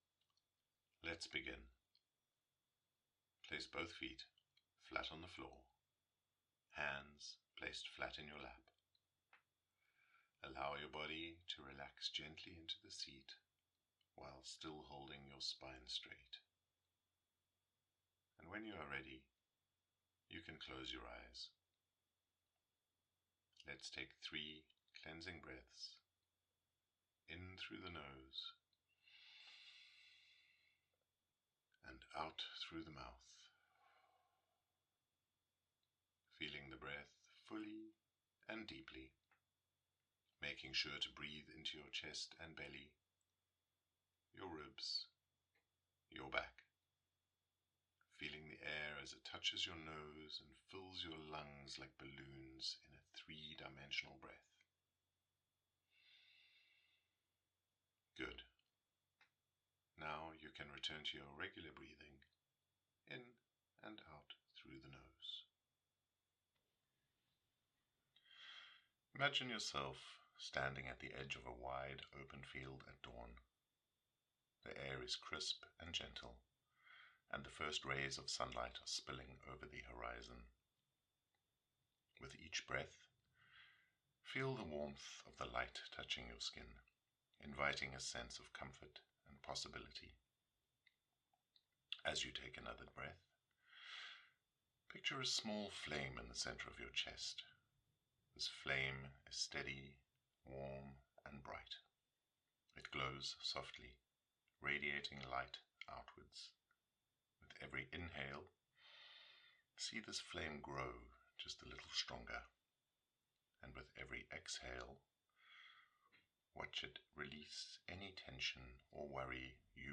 Star Weavers Meditation
WS33-meditation-Star-Weavers.mp3